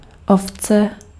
Ääntäminen
Paris: IPA: [mu.tɔ̃]